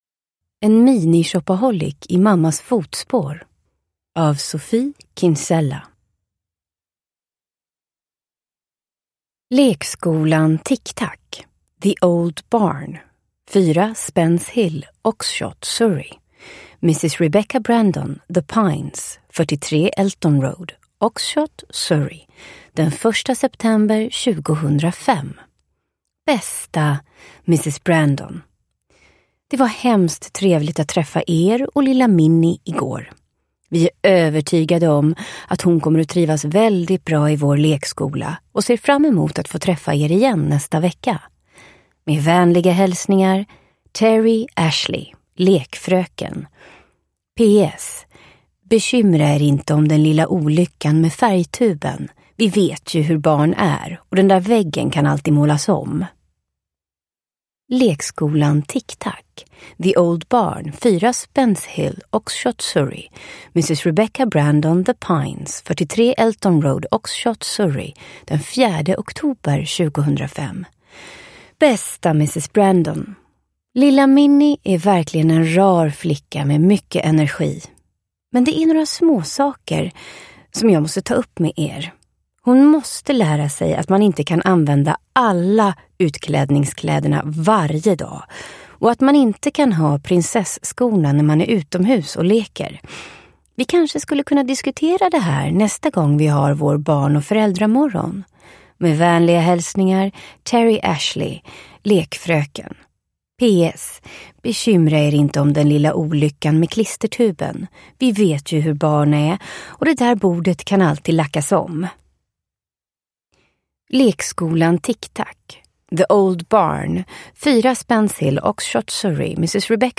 En mini-shopaholic i mammas fotspår – Ljudbok – Laddas ner